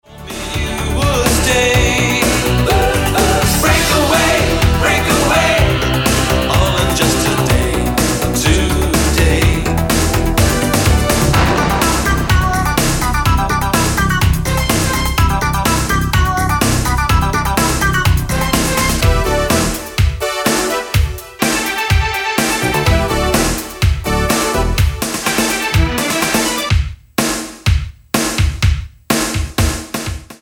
The Disco Star of the 80s!